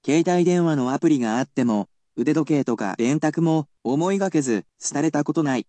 I will also tell you the pronunciation of the word using the latest in technological advancements.